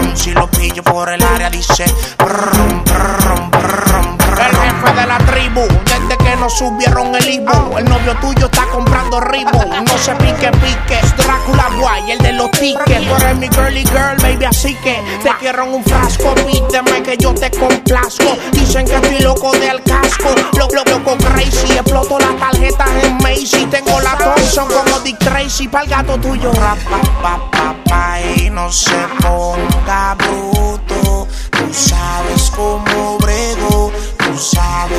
Hip-Hop Rap Latin Urbano latino
Жанр: Хип-Хоп / Рэп / Латино